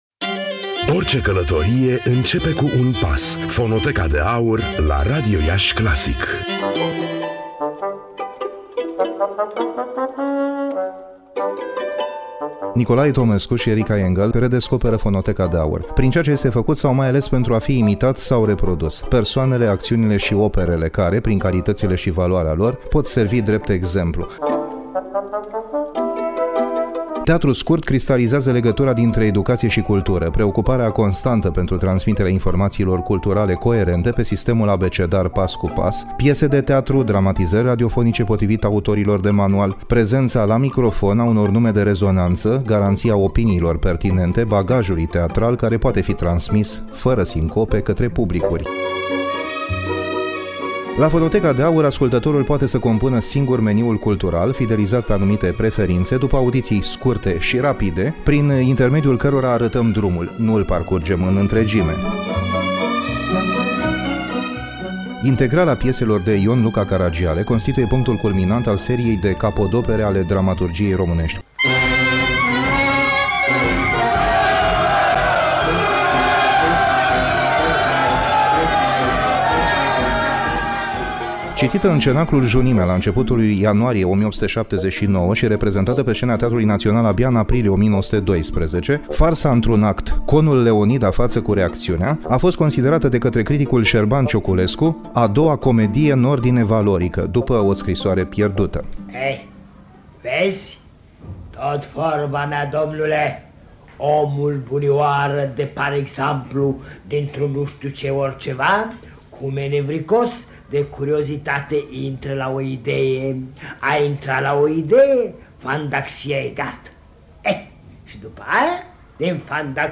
Înainte de a răspunde, să ne amintim sau să descoperim secvenţe din Fonoteca Radio Iaşi, din Fonoteca Societăţii Române de Radiodifuziune…
Scenete-mp3.mp3